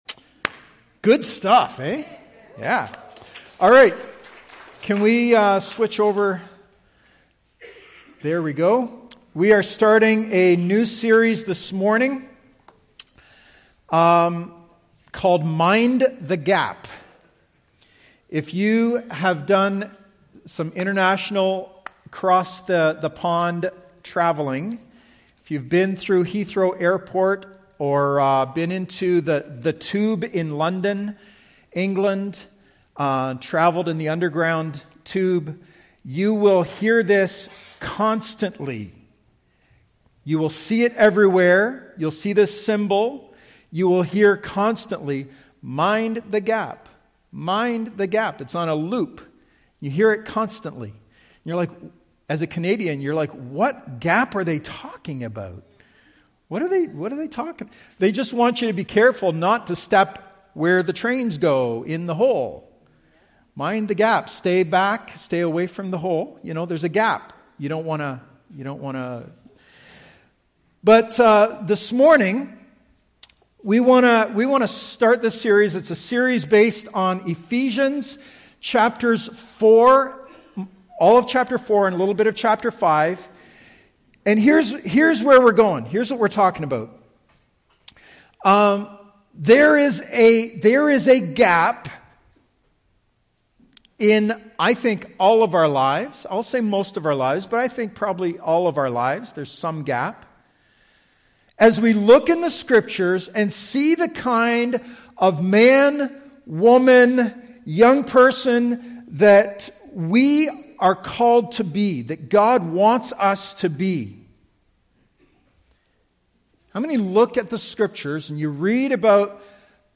Sermons | Evangel Bathurst
AM Service/ Living Worthy